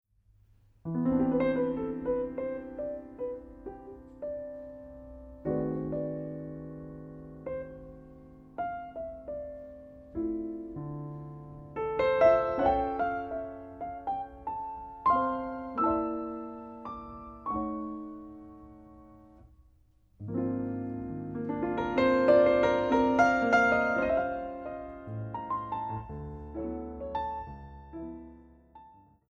Solo Piano Concert
Recording: Ralston Hall, Santa Barbara, CA, January, 2008
Piano